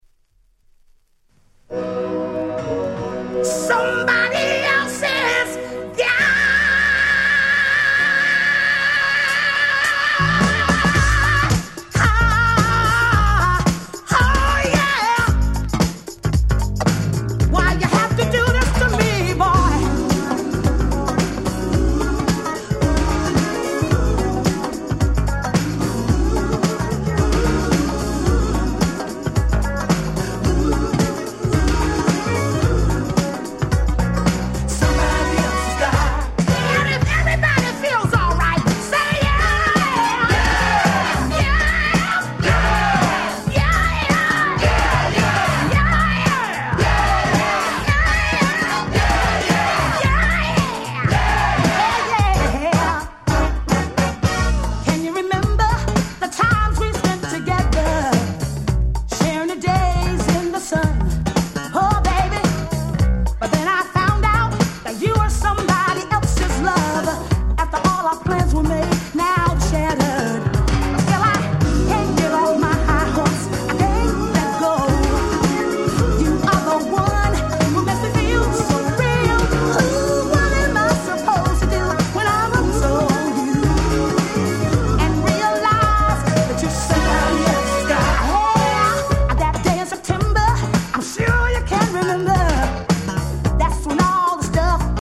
Super Dance Classics !!